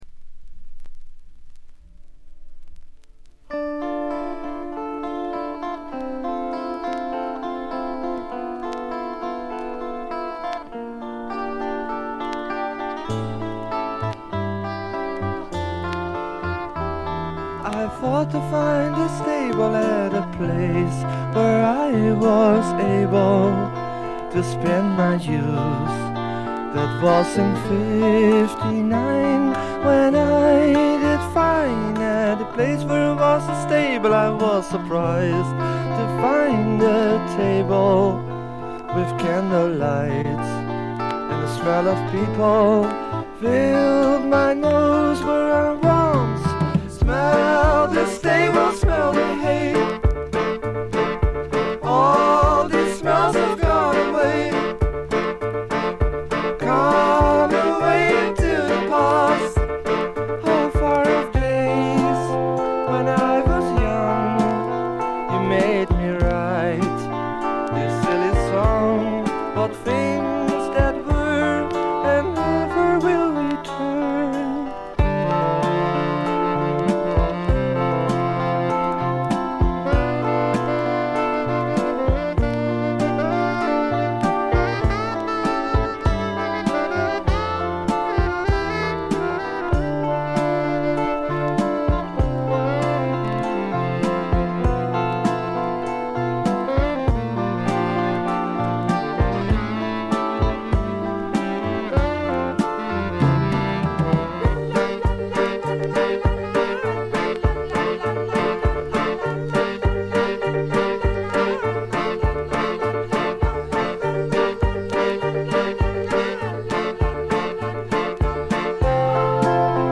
ざっと全編試聴しました。バックグラウンドノイズ、チリプチやや多め大きめ。
全体はチェンバー・ロック風な雰囲気ですが、フォークロックとか哀愁の英国ポップ風味が濃厚ですね。
試聴曲は現品からの取り込み音源です。